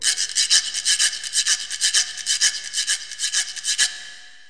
guiro.mp3